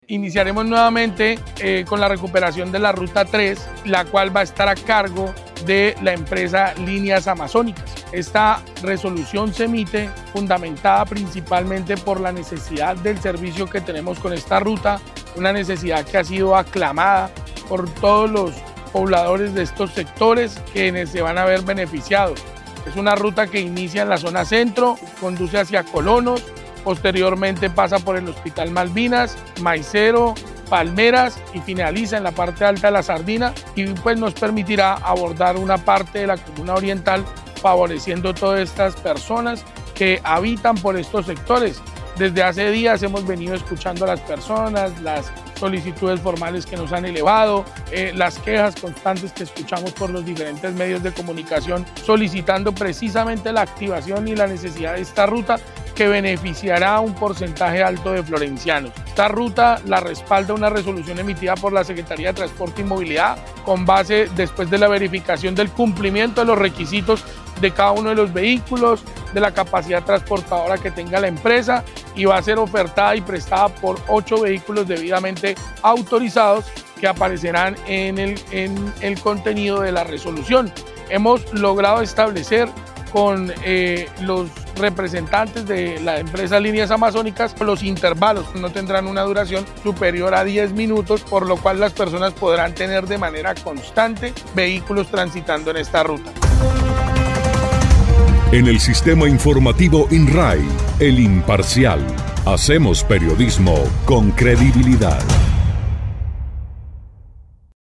El secretario de Transporte y Movilidad, Jaime Eduardo Becerra Correa, dijo que dicho recorrido estará a cargo de la empresa Líneas Amazonas, la cual comenzará a operar a partir de hoy lunes 5 de mayo de 2025, con frecuencia de despacho de cada 10 minutos.